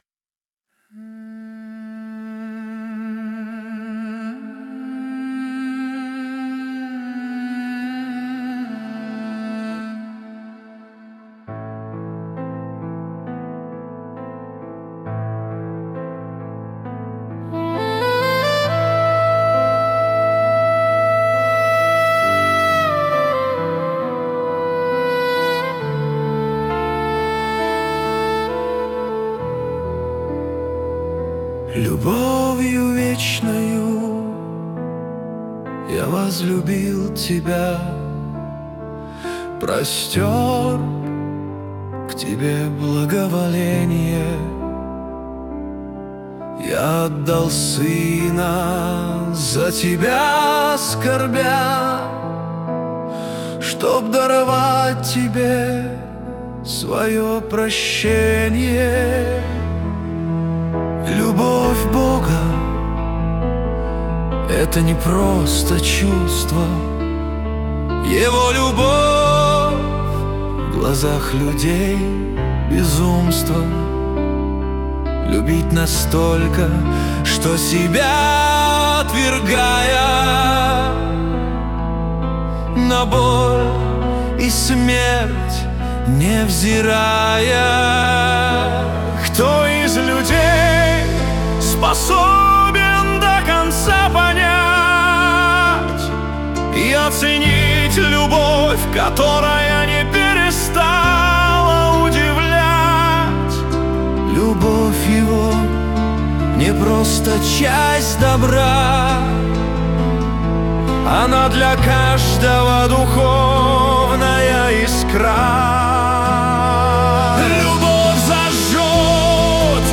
песня ai
178 просмотров 767 прослушиваний 74 скачивания BPM: 67